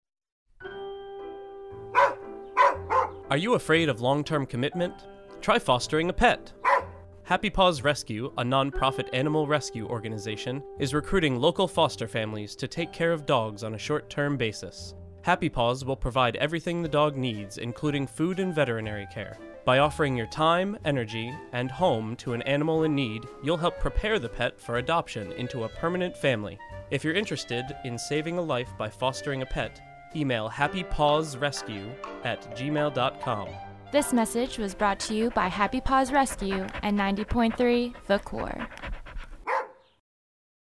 J radio PSA
FinishedHappyPawsPSA.mp3